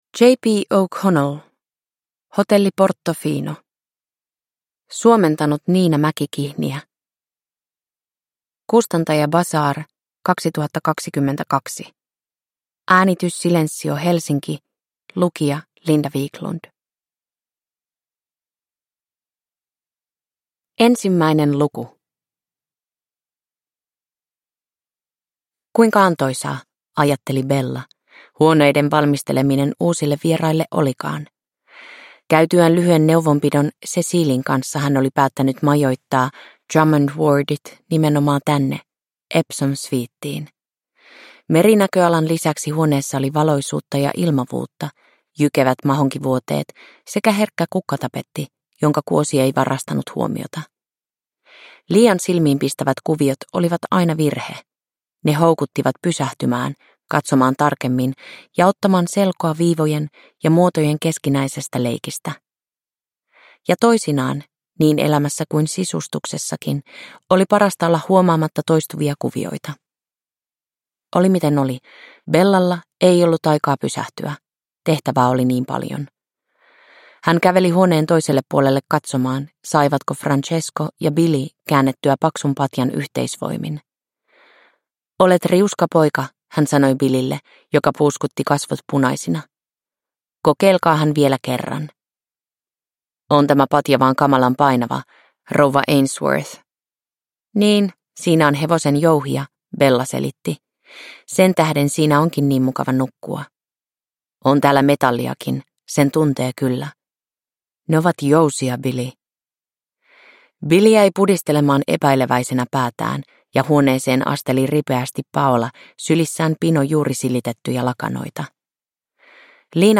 Hotelli Portofino – Ljudbok – Laddas ner